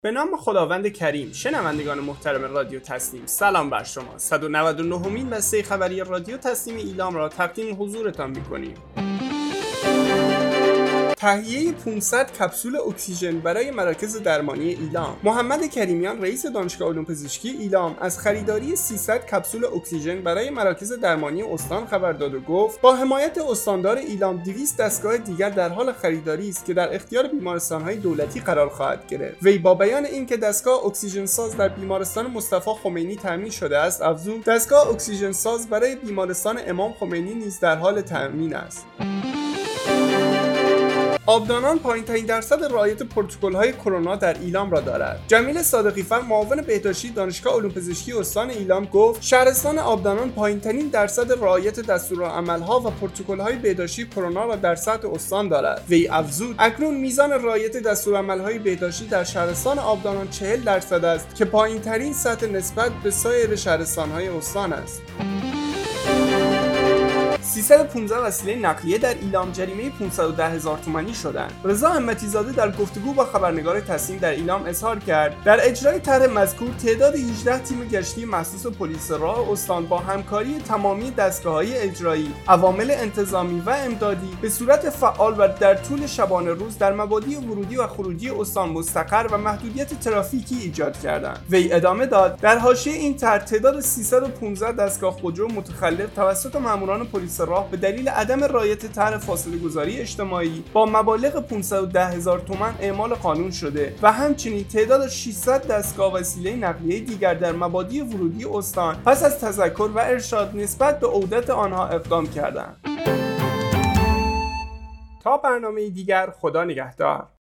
گروه استان‌ها- آخرین و مهمترین اخبار استان ایلام در قالب بسته خبری